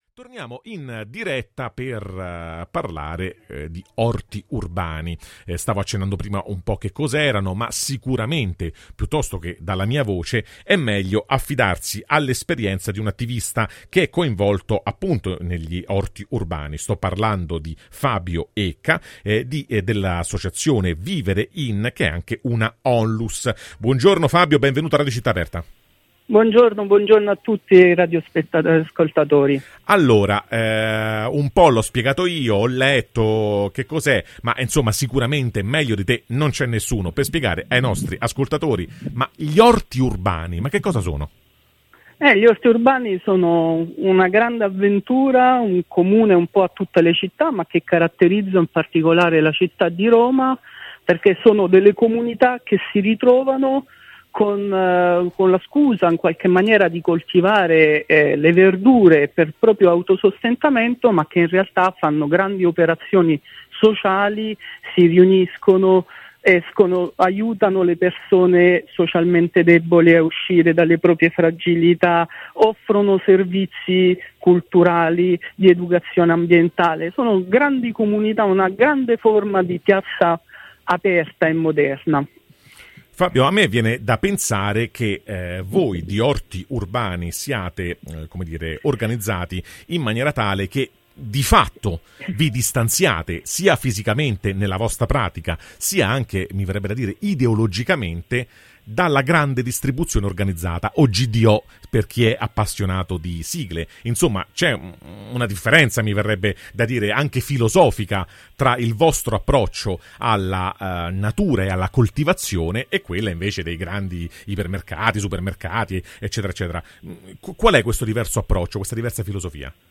La Grande Distribuzione qui non c’entra un “cavolo”: spieghiamo per bene il fenomeno “Orti Urbani” [intervista